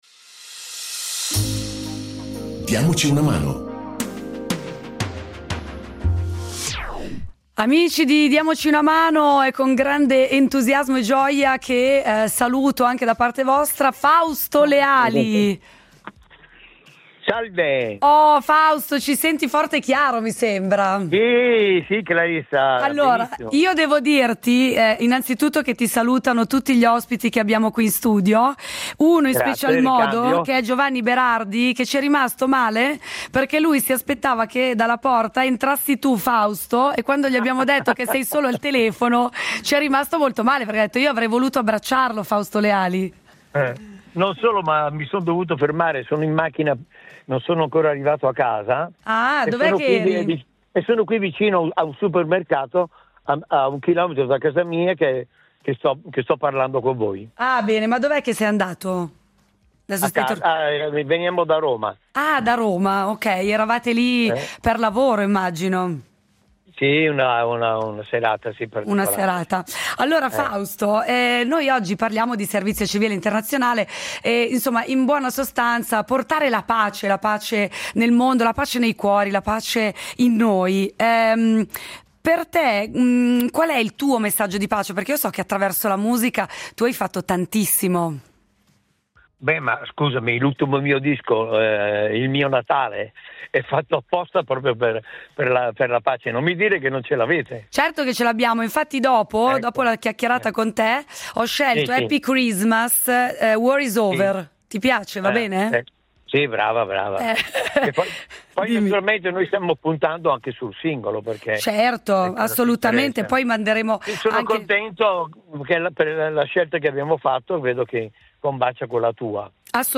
Incontro con il cantautore Fausto Leali